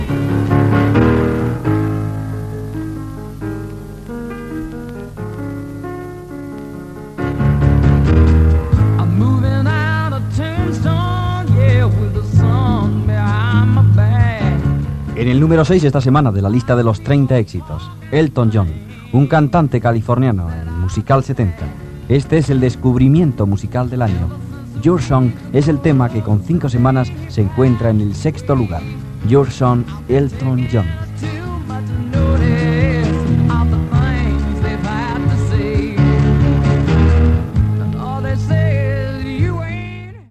Identificació del programa, tema del cantant Elton John i posició a la llista d'èxits
Musical